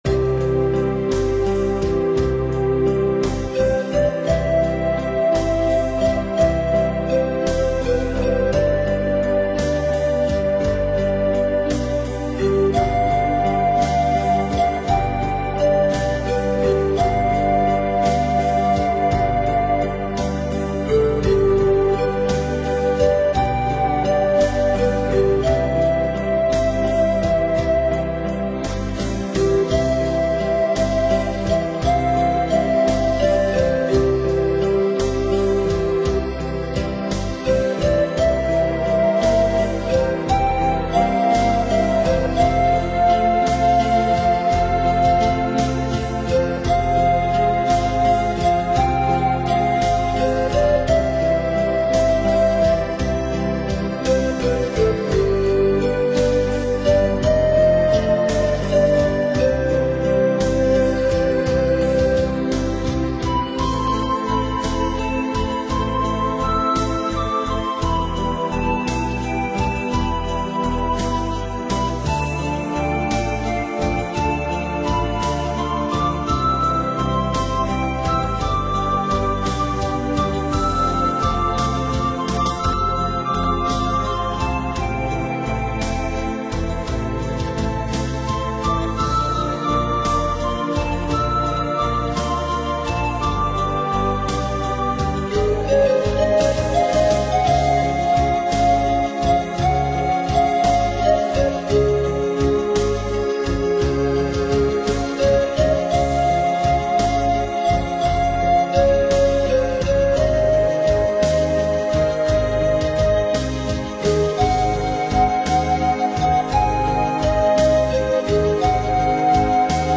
新世纪大自然的乐章，从打开天空一曲开始，寻找原野的一片宁静。